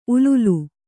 ♪ ululu